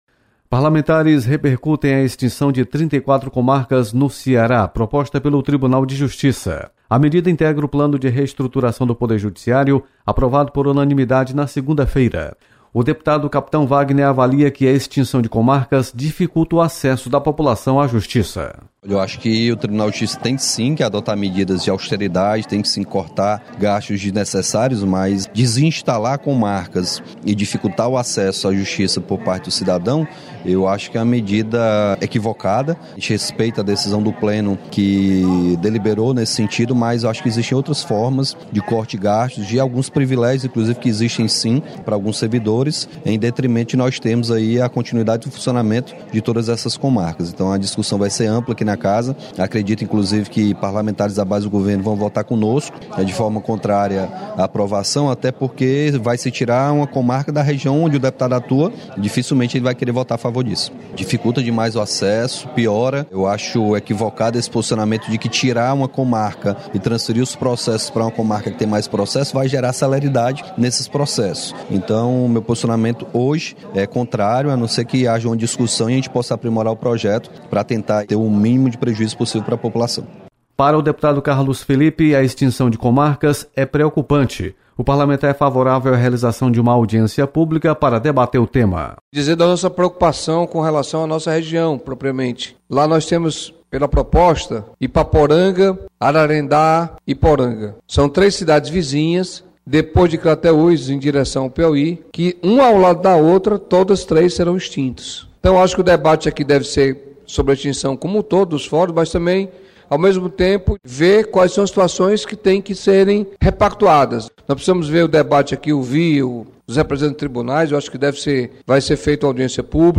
Parlamentares comentam sobre extinção de comarcas. Repórter